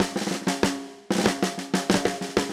AM_MiliSnareA_95-01.wav